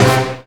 JAZZ STAB 31.wav